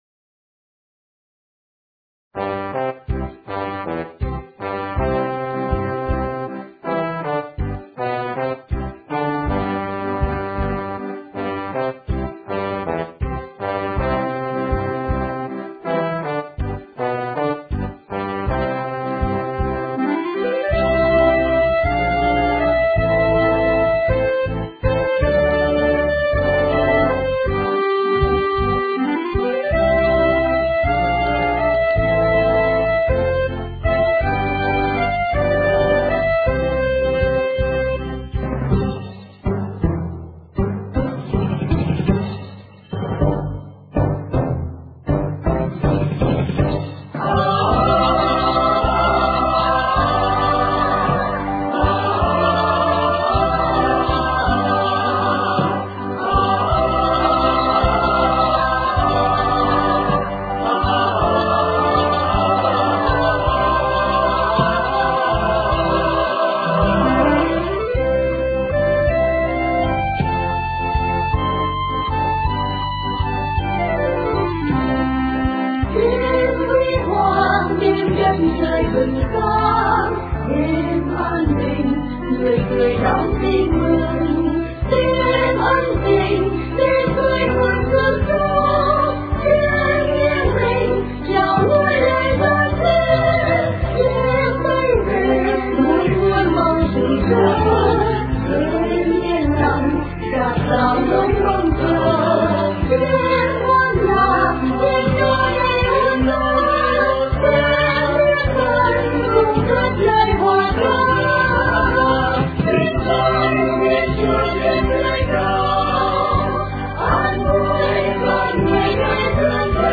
* Thể loại: Noel